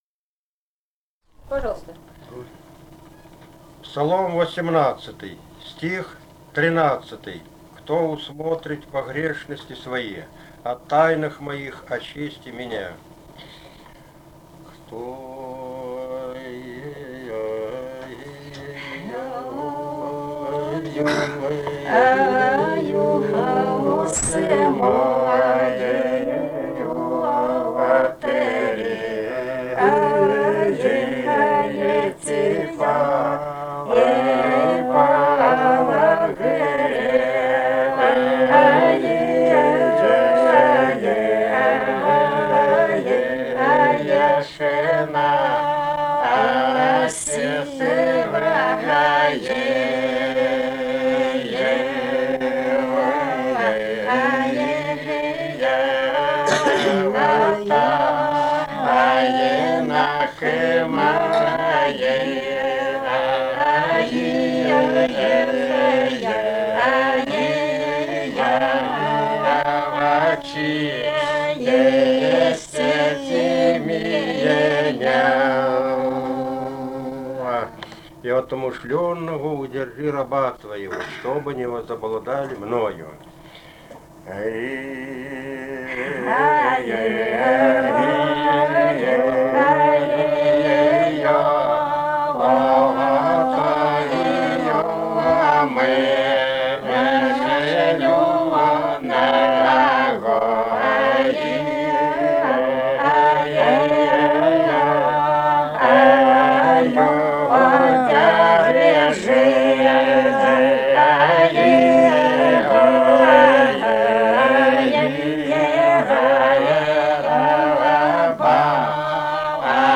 Этномузыкологические исследования и полевые материалы
Грузия, г. Тбилиси, 1971 г.